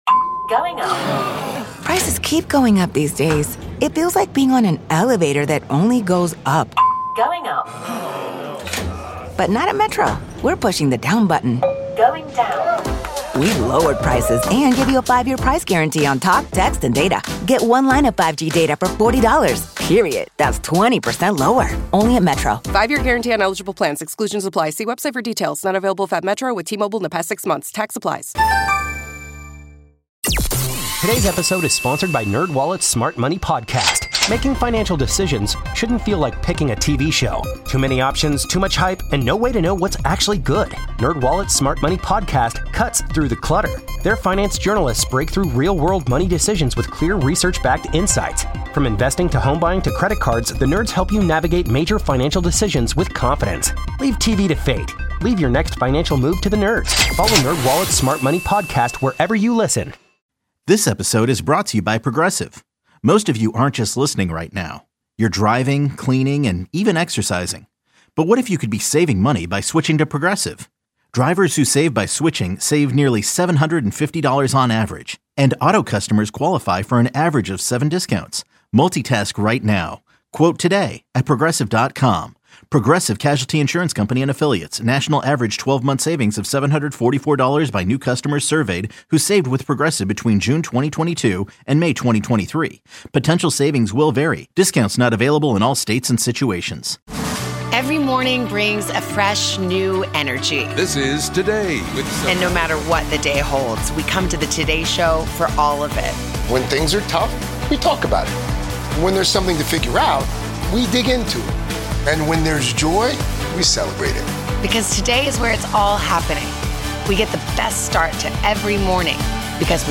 For more than 25 years, The Junks have owned Washington D.C. sports radio, covering Commanders, Nationals, Capitals, Wizards, Hokies, Terrapins, and Hoyas news.